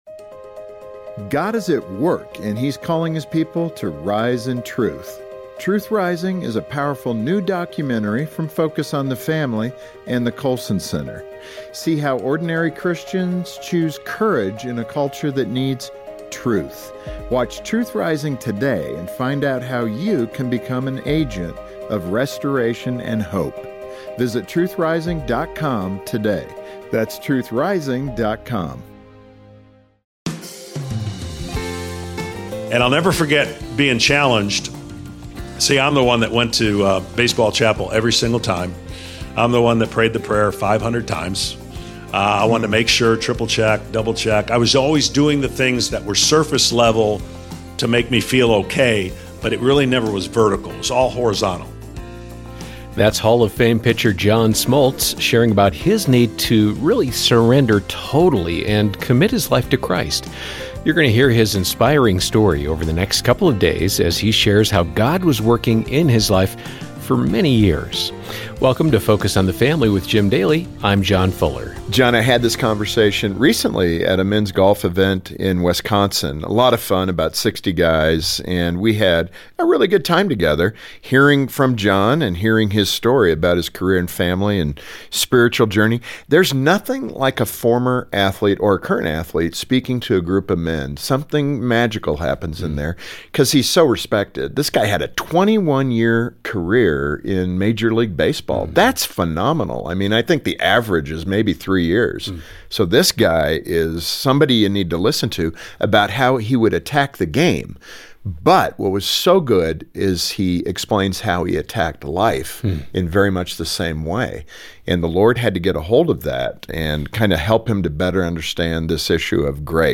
He shares his inspiring story about the twists and turns in his career, finding faith in Christ, and rebuilding his life after experiencing hardship. He reveals how God taught him to surrender control and trust Him, while growing in grace in this one-on-one conversation with Jim Daly.